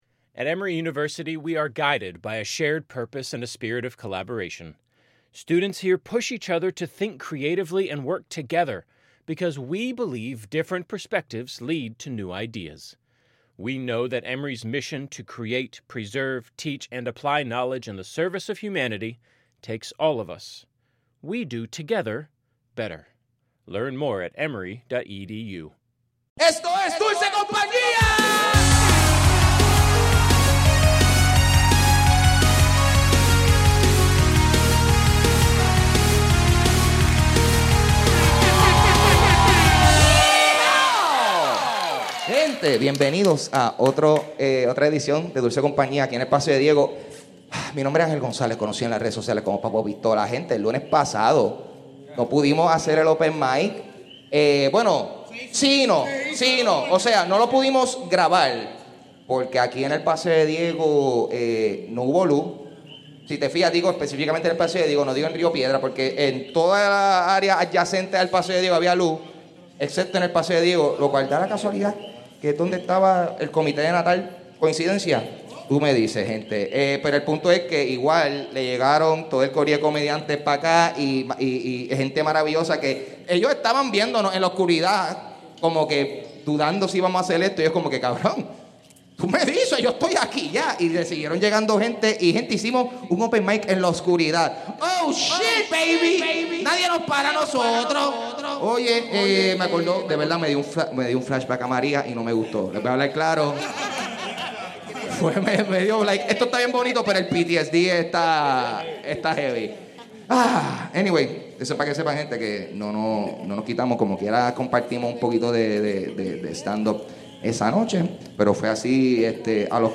Regresamos al Paseo de Diego a reflexionar luego de las elecciones.